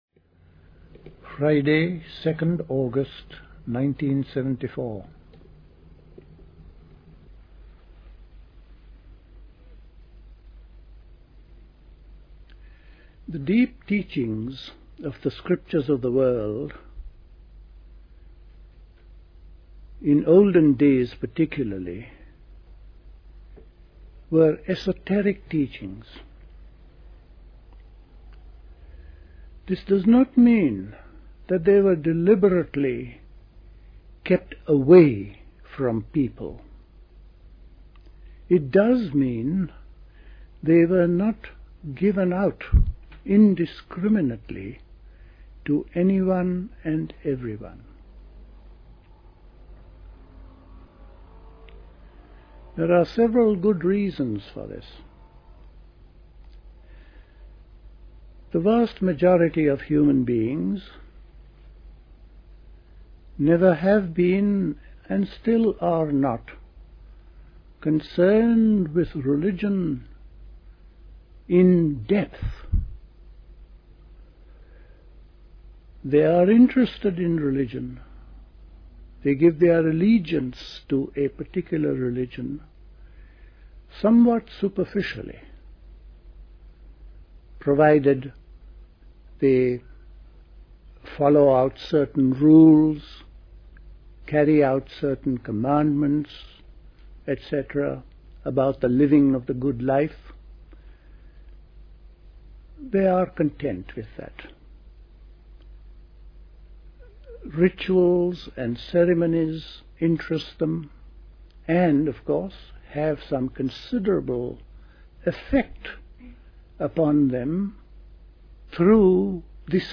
A talk
at Dilkusha, Forest Hill, London on 2nd August 1974